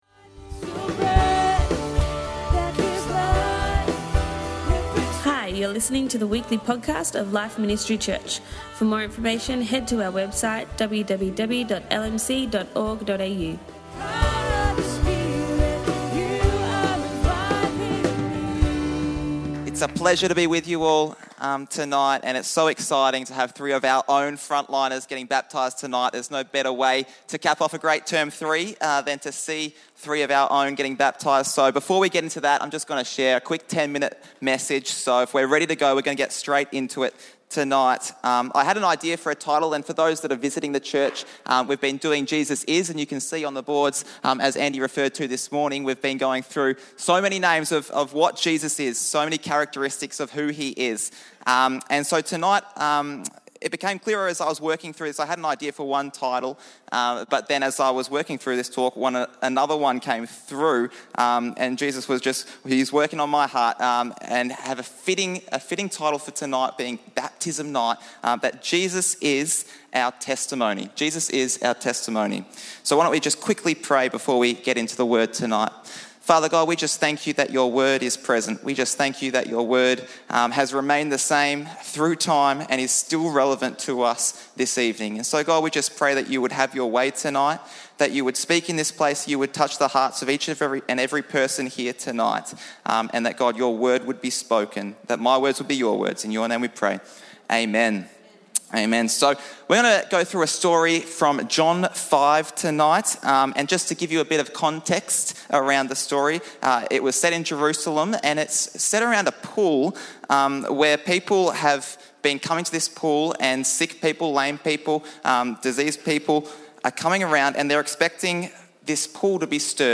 At this service, we had 3 people get baptised!